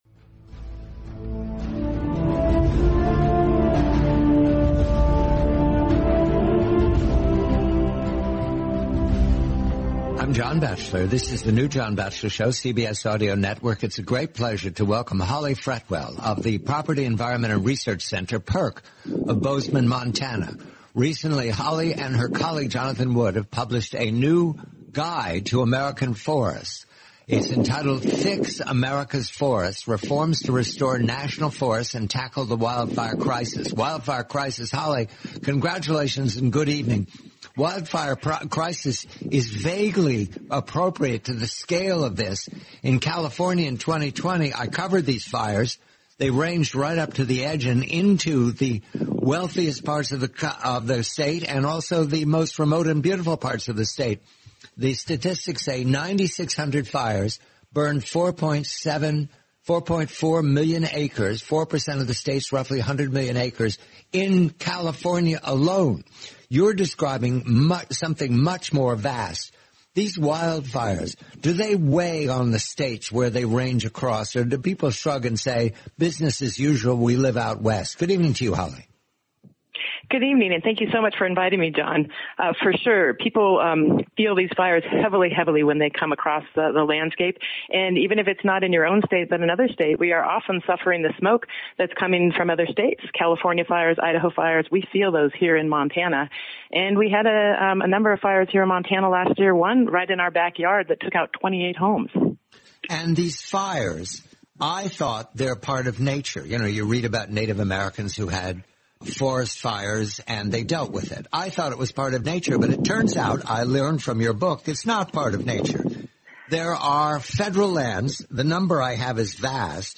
The complete forty-minute interview.